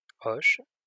Hoche (French: [ɔʃ]